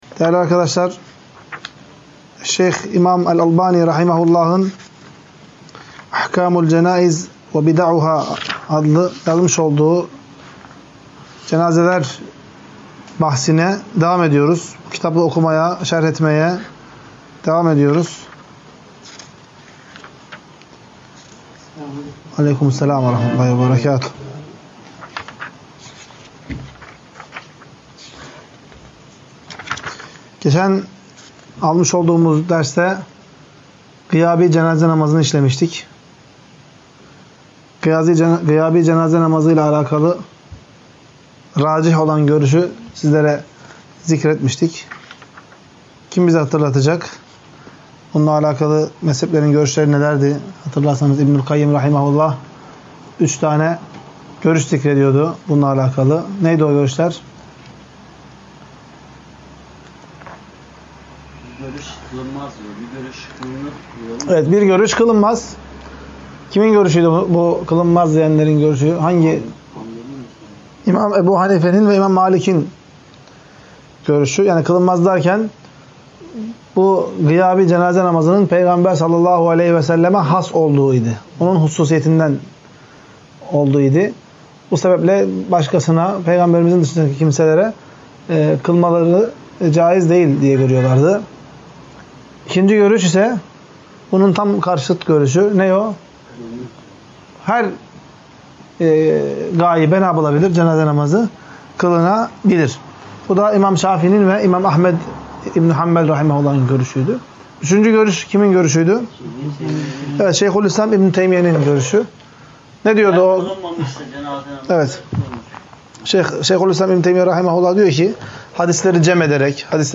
11. Ders - CENÂZE AHKÂMI VE CENÂZEDE YAPILAN BİDATLER - Taybe İlim